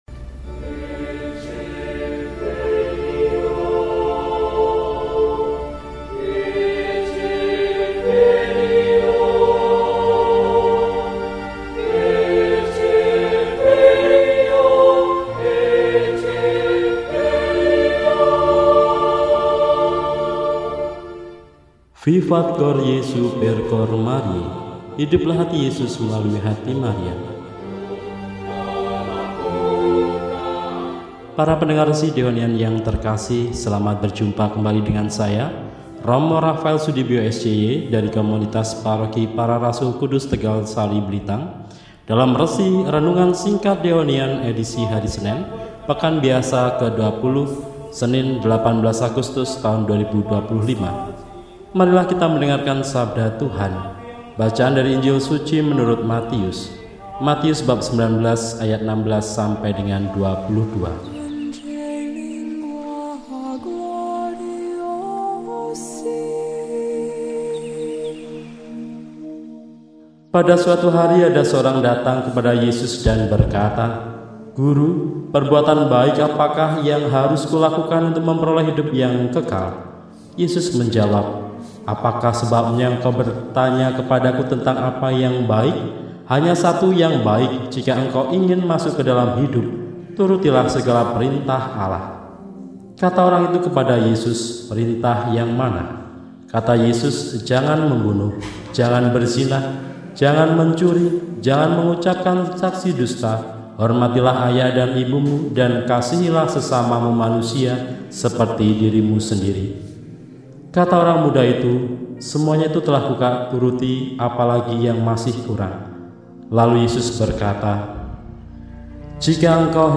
Senin, 18 Agustus 2025 – Hari Biasa Pekan XX – RESI (Renungan Singkat) DEHONIAN